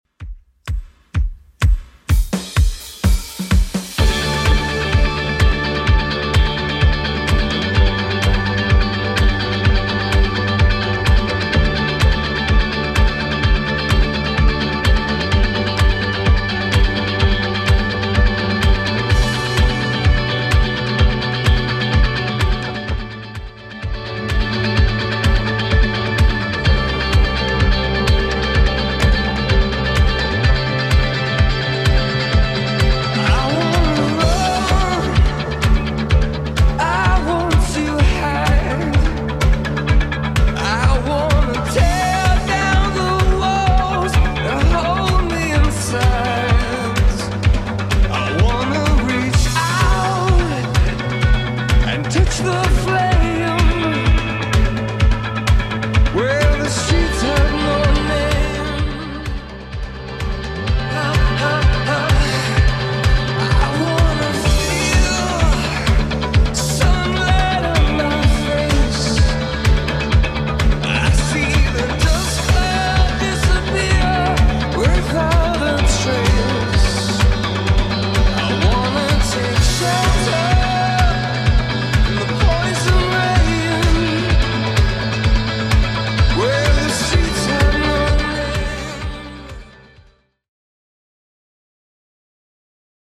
Genre: 80's
BPM: 105